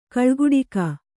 ♪ kaḷguḍika